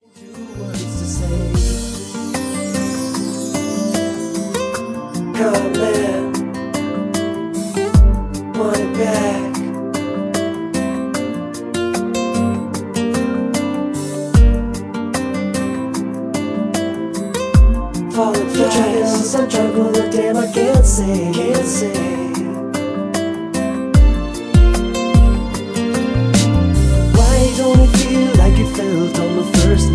(Key-F) Karaoke MP3 Backing Tracks
Just Plain & Simply "GREAT MUSIC" (No Lyrics).